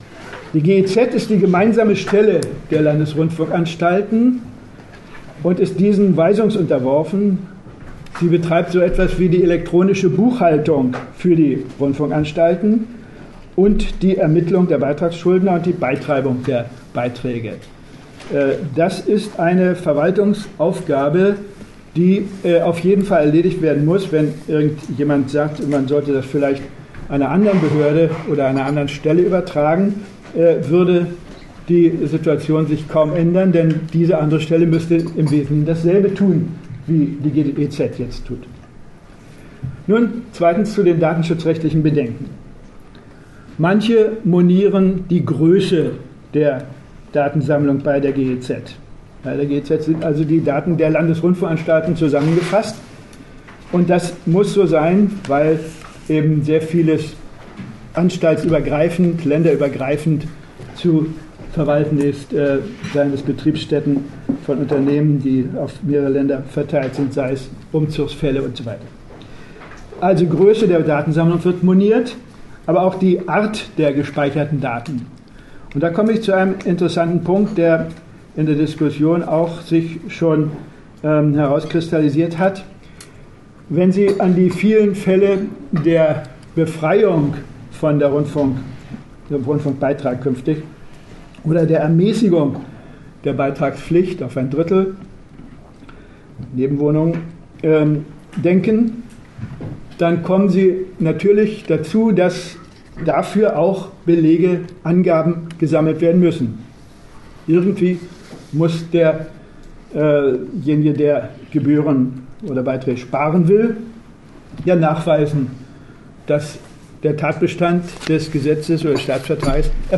Pressekonferenz zum Gutachten Wo: ZDF-Hauptstadtstudio, Berlin